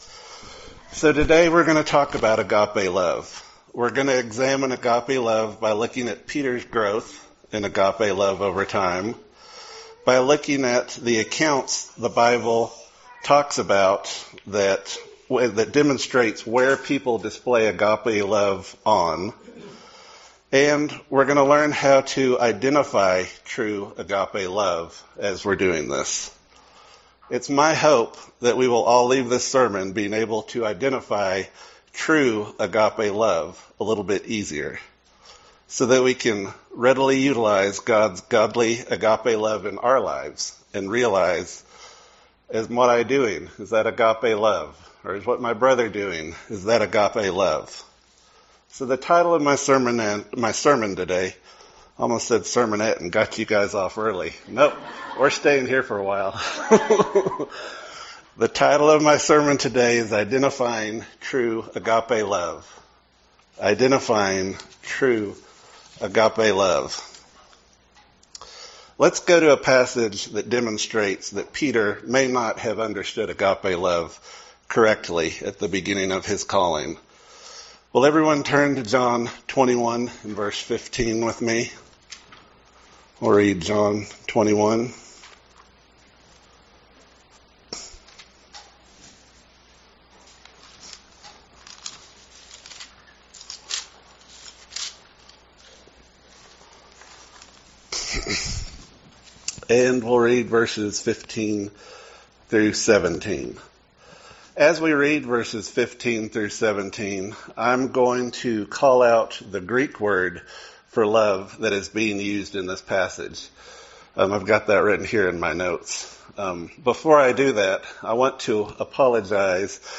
Given in Lubbock, TX